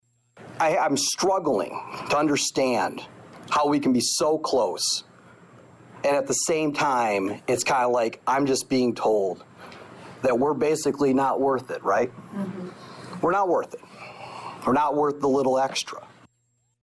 The picketing preceded a school board meeting where the board heard comments from parents along with some current and retired staff.